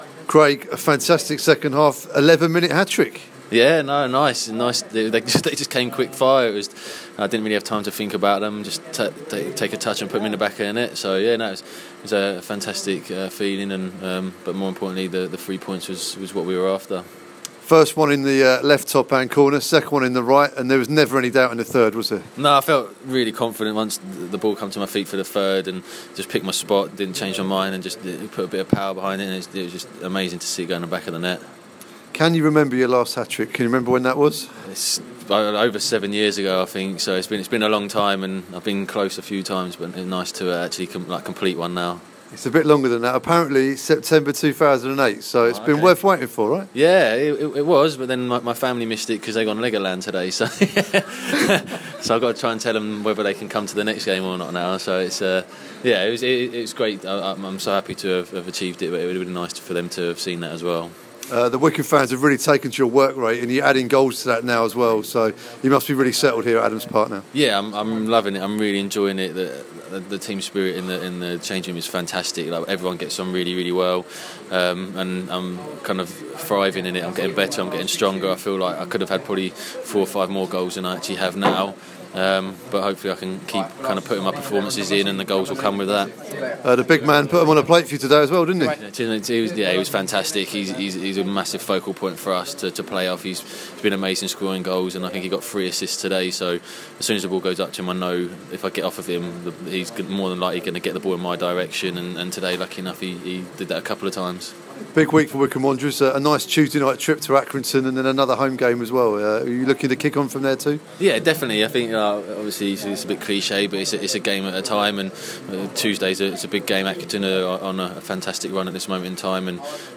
Craig Mackail-Smith post Crawley interview